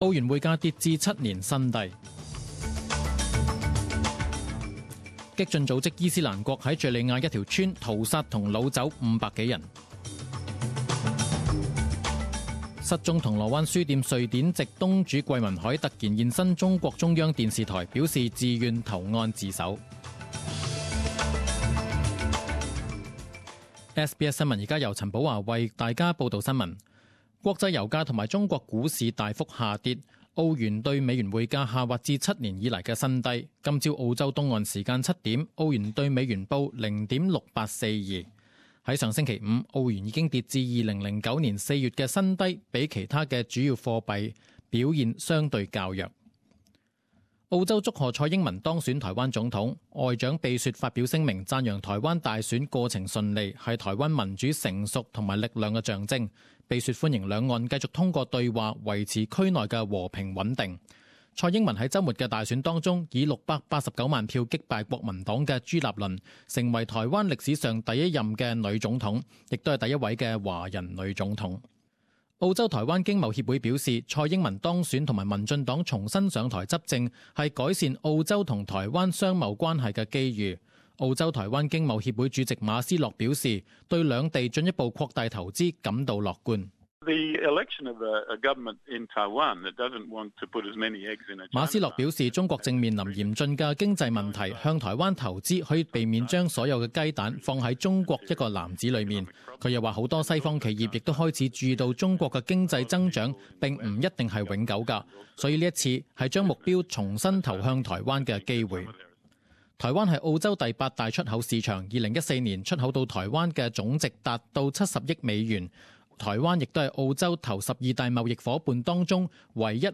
十点钟新闻报导 （一月十八日）